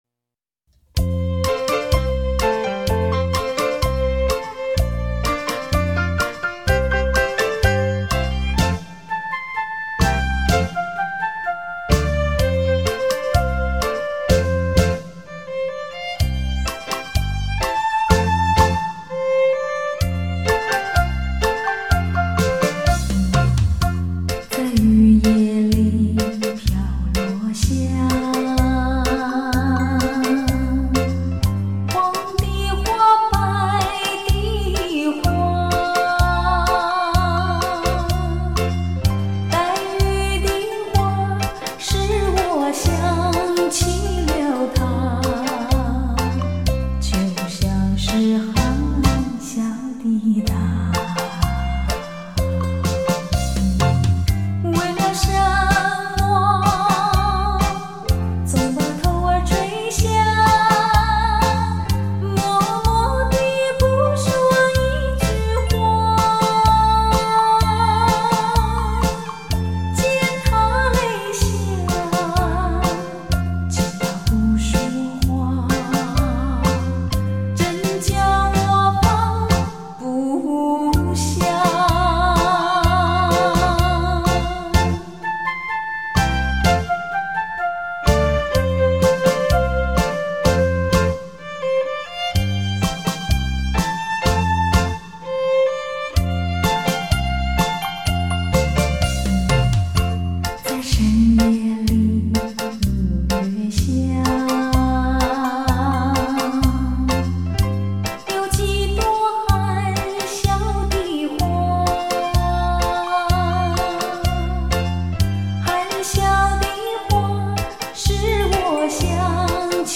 吉鲁巴